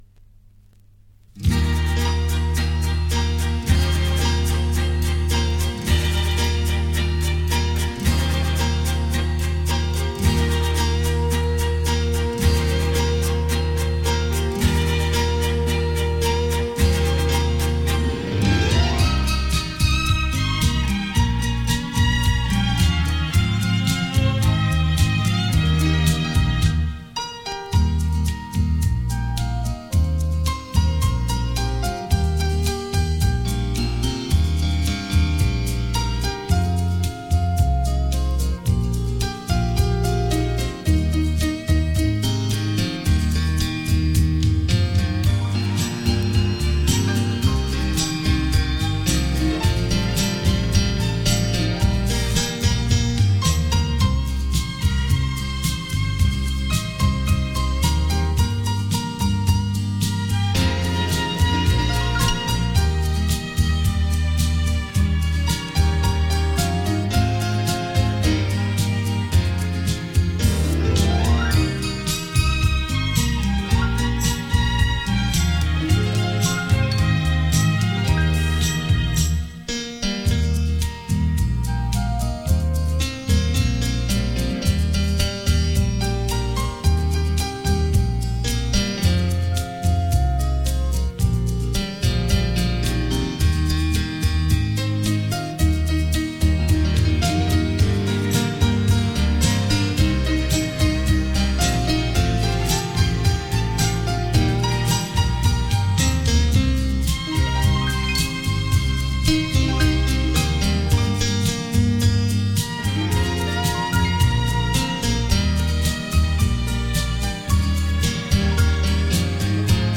令人回味的音韵 仿佛回到过往的悠悠岁月